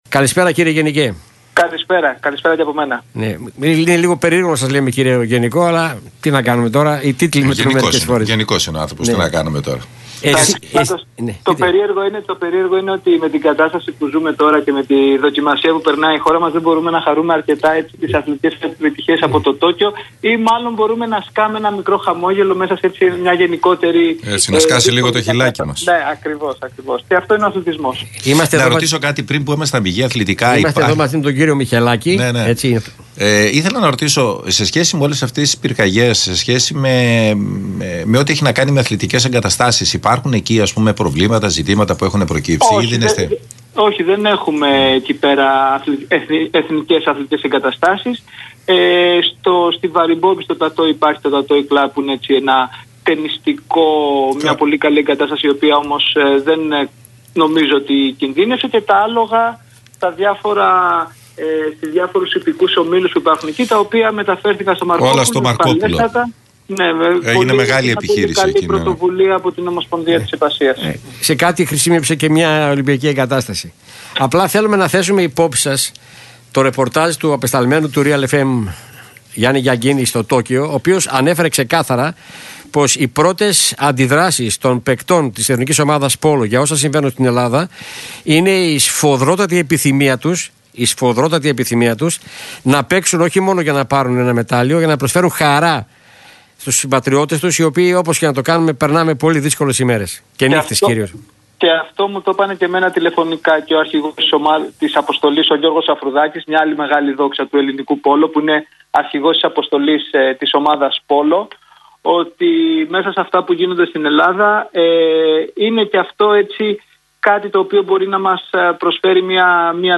Την αισιοδοξία του για την κατάκτηση ενός μεταλλίου από την Eθνική ομάδα πόλο εξέφρασε ο Γενικός Γραμματέας Αθλητισμού και πρώην διεθνής πολίστας, Γιώργος Μαυρωτάς, μιλώντας στον «RealFm 97,8».